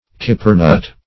kippernut - definition of kippernut - synonyms, pronunciation, spelling from Free Dictionary Search Result for " kippernut" : The Collaborative International Dictionary of English v.0.48: Kippernut \Kip"per*nut`\, n. (Bot.)